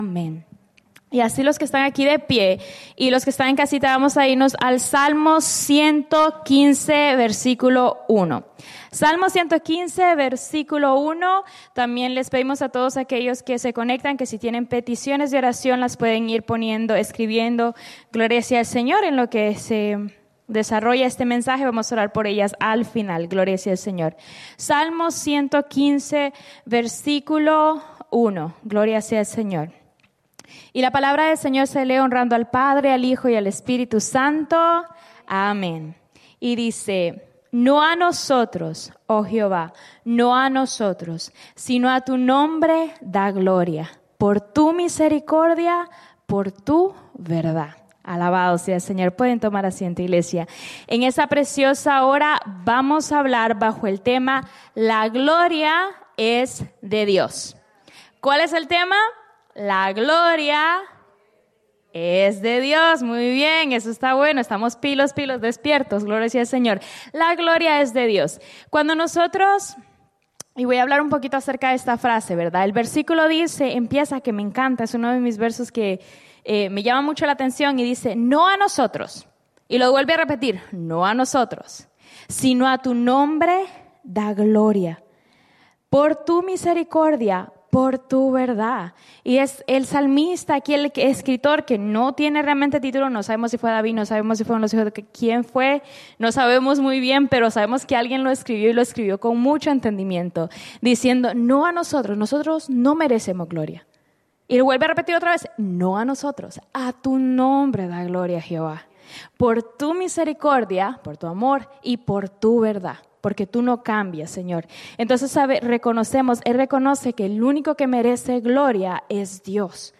en la Iglesia Misión Evangélica en Souderton, PA